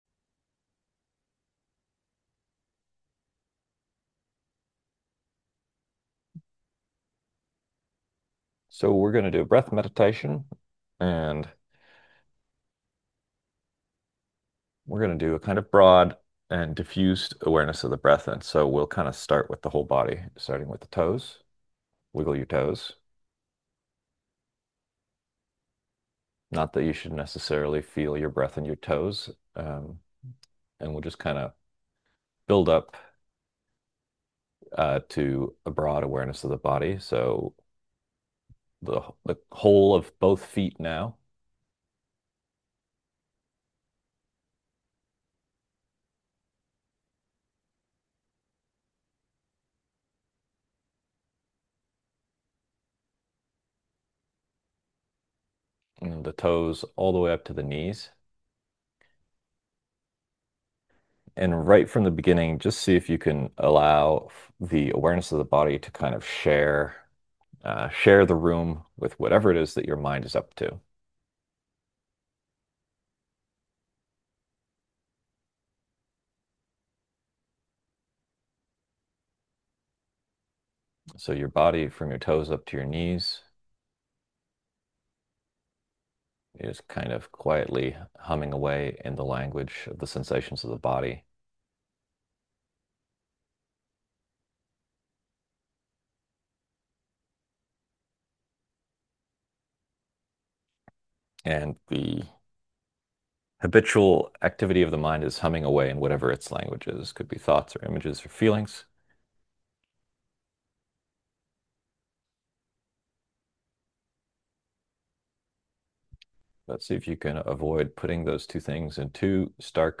Podcast (guided-meditations): Play in new window | Download